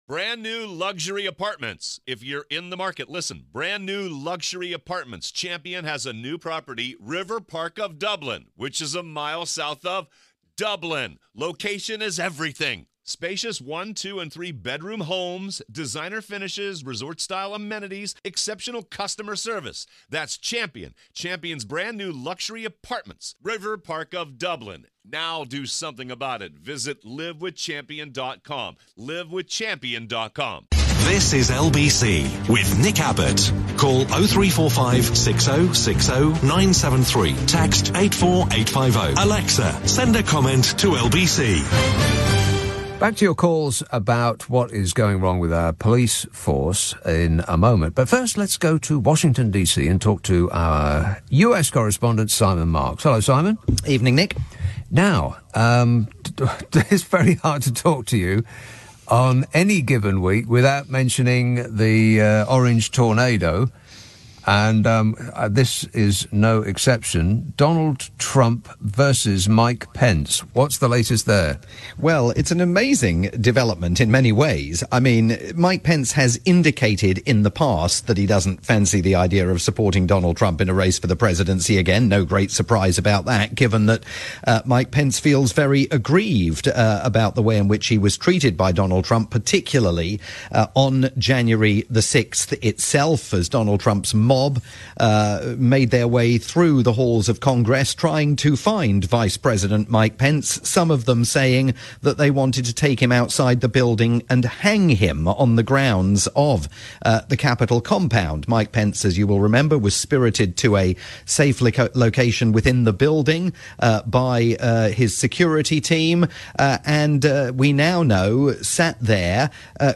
live roundup
late night programme on the UK's LBC